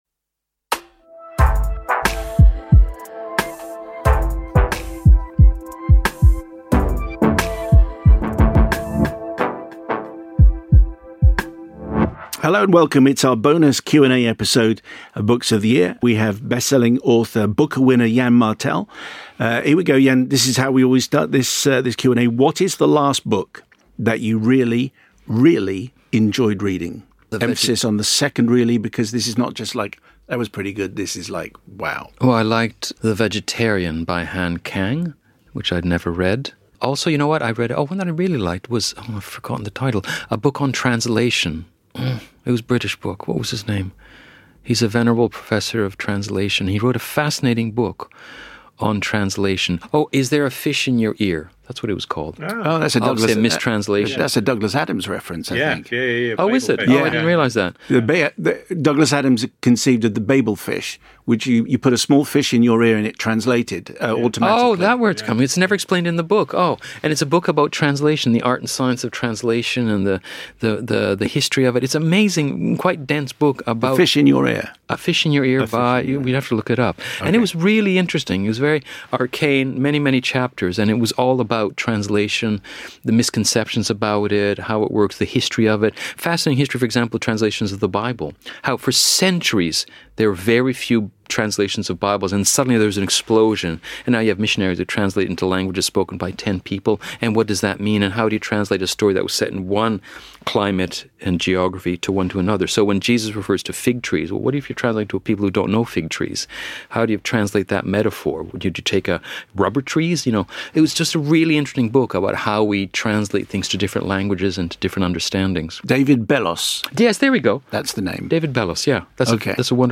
boty_yann_martel_qna_final_7bf533f3_normal.mp3